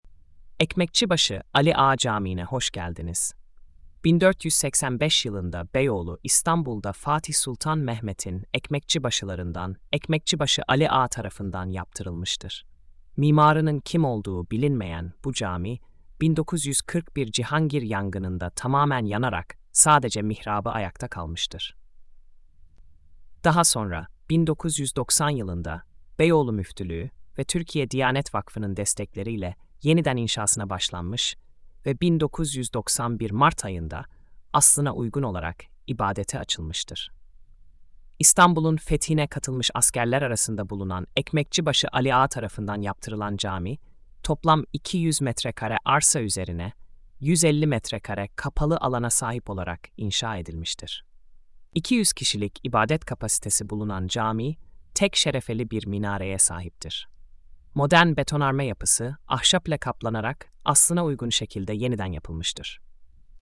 Sesli Anlatım